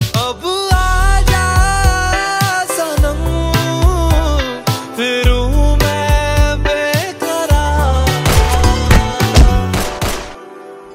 électronique